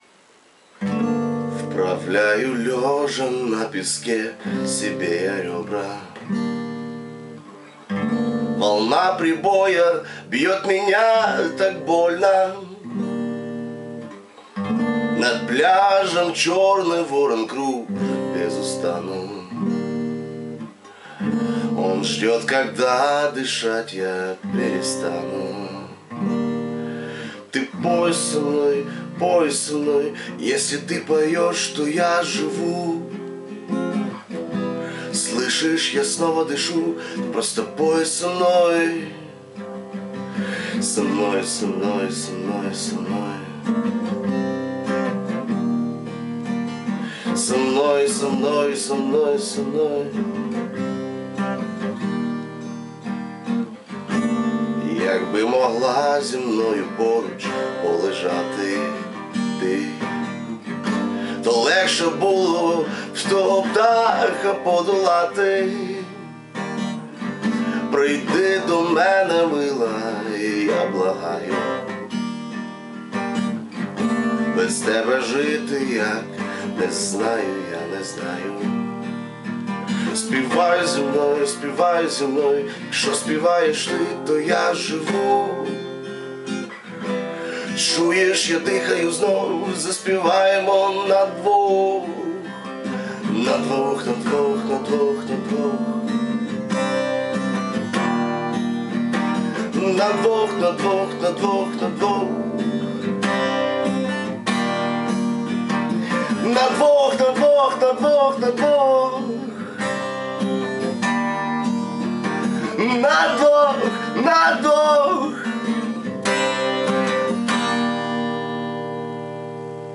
ТИП: Пісня
СТИЛЬОВІ ЖАНРИ: Ліричний
shr Оригінальна пісня, яка співається двумя мовами!
на цьому записі Ваш голос звучить нижче, навіть лячно на початку biggrin
Не так давно я захопився гроулінгом, так шо вокал в цій пісні — ще не зовсім лячно biggrin